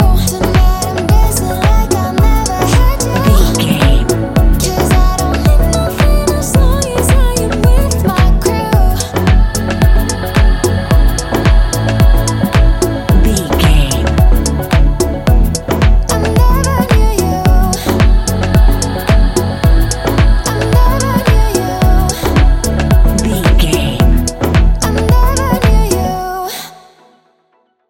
Ionian/Major
F♯
house
electro dance
synths
techno
trance